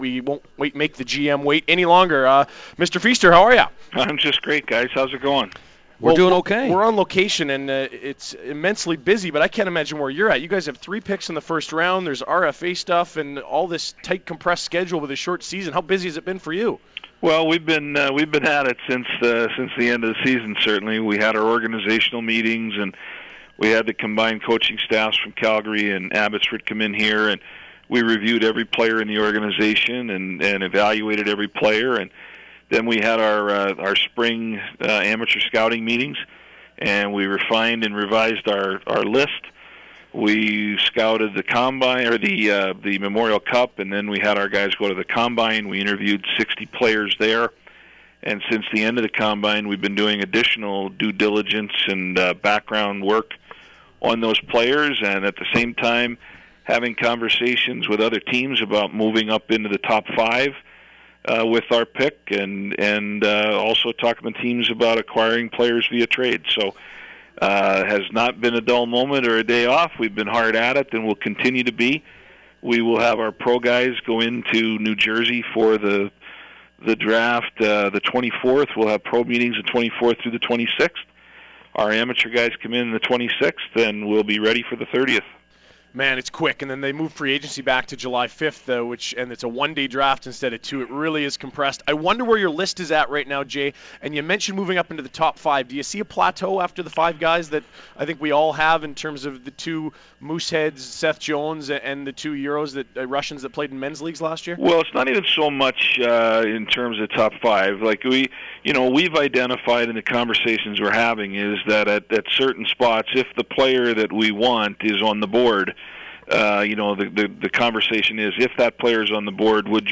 Feaster interview on the FAN